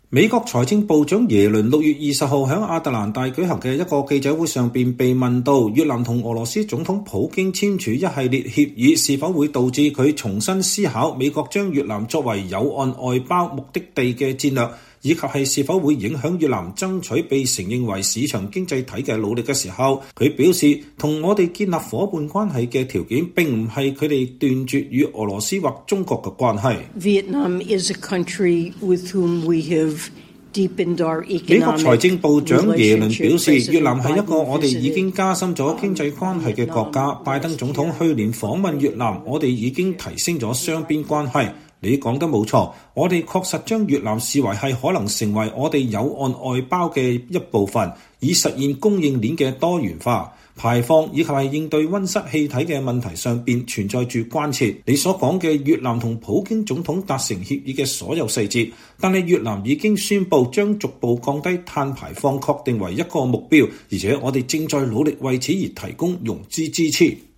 美財政部長珍妮特·耶倫(Janet Yellen)6月20日在亞特蘭大舉行的一個記者會上被問到，越南與俄羅斯總統普京簽署一系列協議是否會導致她重新思考美國把越南作為「友岸外包」目的地的戰略以及是否會影響越南爭取被承認為市場經濟體的努力時說。“